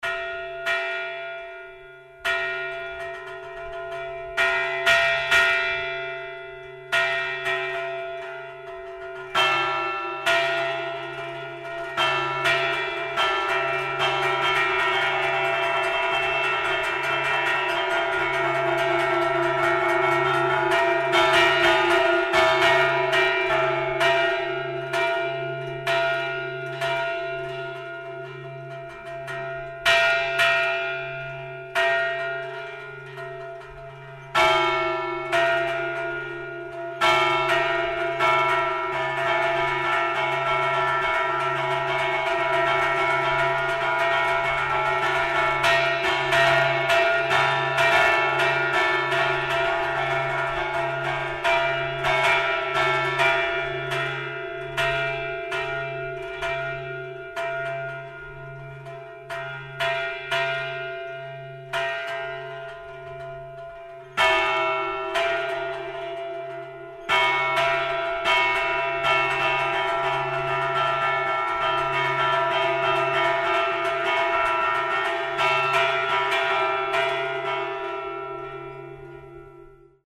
Toques de campanas en formato MP3
Repique de fiesta 1:20 fiesta repique